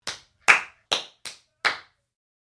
Hand Claps
Hand Claps is a free sfx sound effect available for download in MP3 format.
yt_kmVnpj1Urjs_hand_claps.mp3